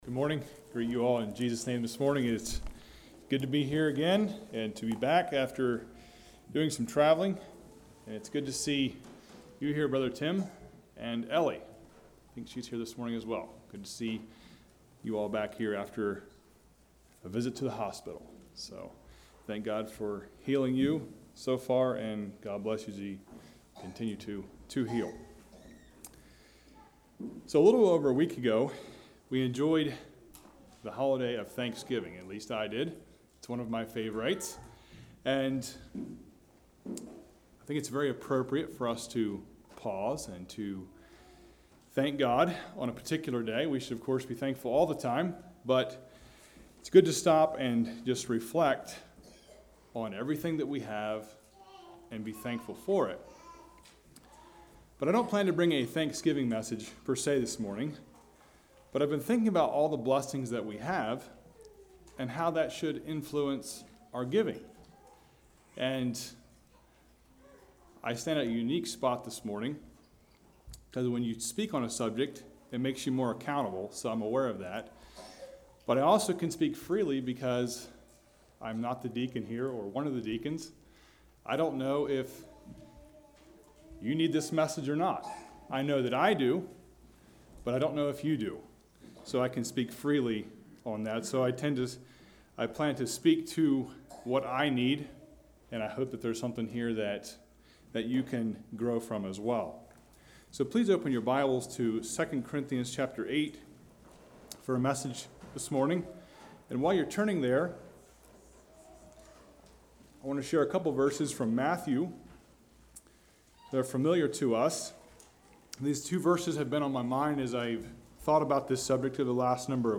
Website of Pilgrim Conference | Christian Sermons, Anabaptist e-Literature, Bible Study Booklets | Pilgrim Ministry
Play Now Download to Device Freely Ye Have Received Freely Give Congregation: Altoona Speaker